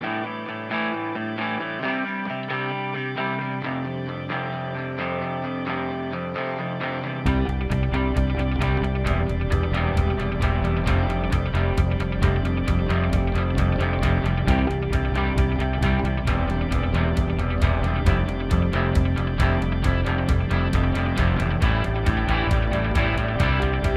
No Lead Guitar Rock 3:50 Buy £1.50